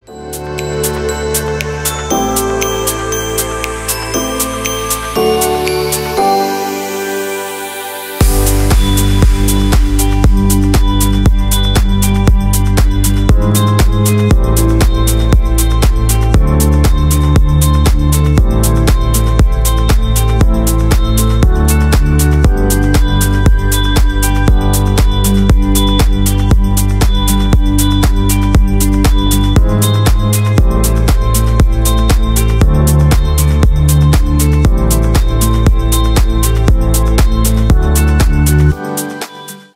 Поп Музыка
клубные
без слов